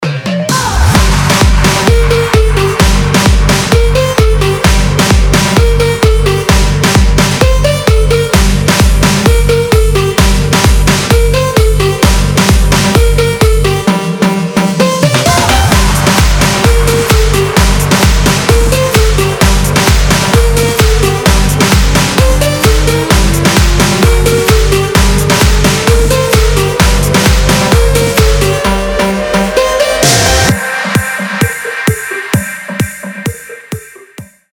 • Качество: 320, Stereo
Progressive Electronic House Music